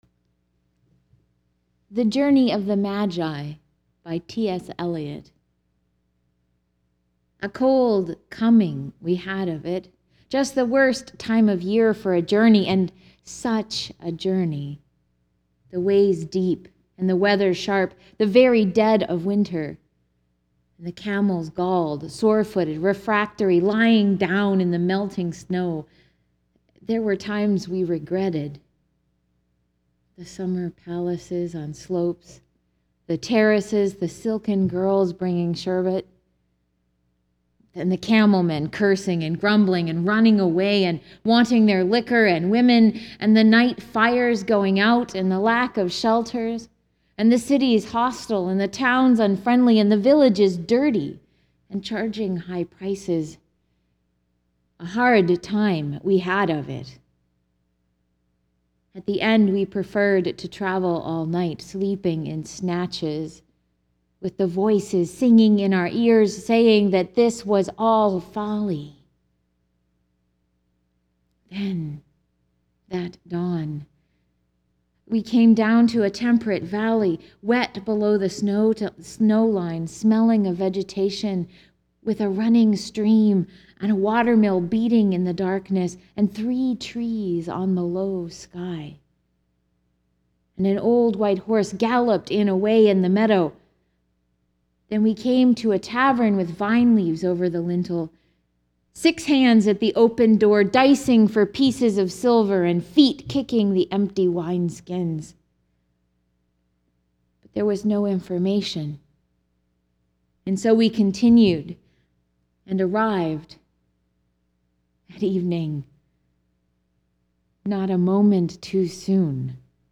Journey of the Magi by T.S. Elliot, the poem used at the beginning of this sermon is available here:  The Poetry Archive: Journey of the Magi  You can actually listen to the poet himself read it.